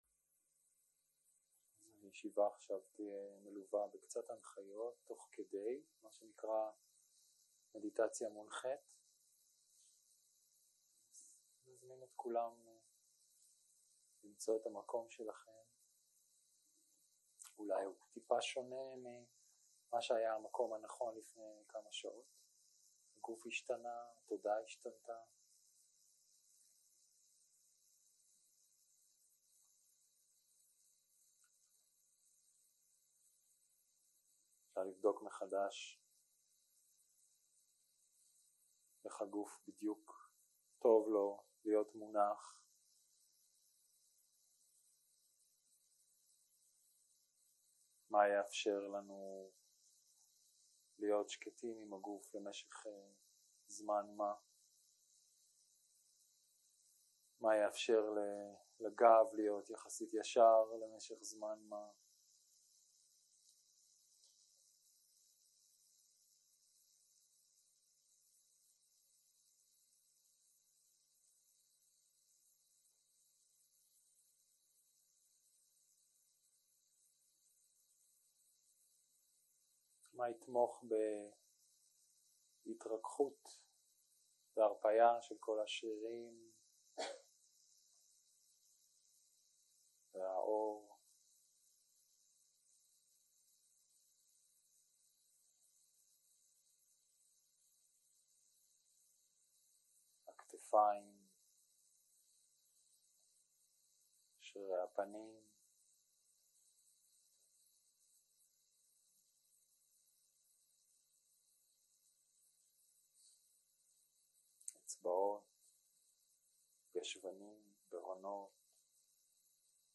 הקלטה 3 - יום 2 - צהרים - מדיטציה מונחית
סוג ההקלטה: מדיטציה מונחית